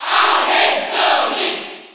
Category:Crowd cheers (SSB4) You cannot overwrite this file.
Sonic_Cheer_French_PAL_SSB4.ogg